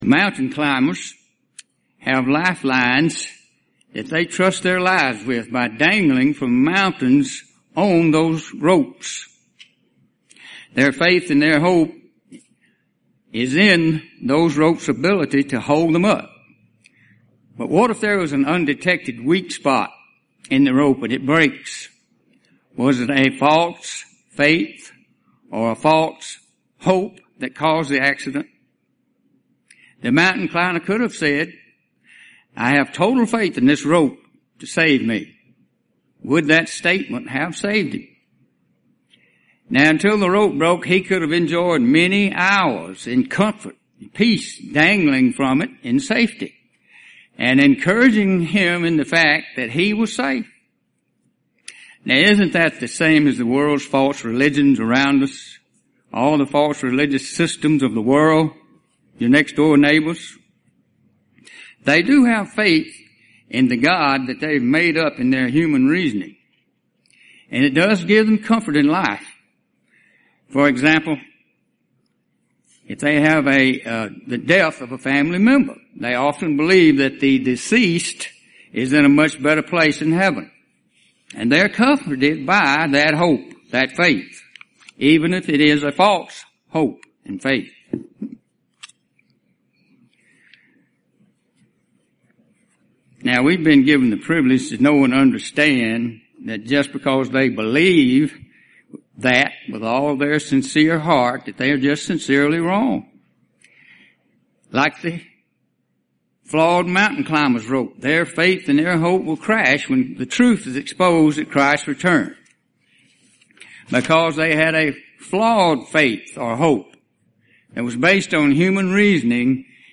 Given in Columbus, GA
UCG Sermon Studying the bible?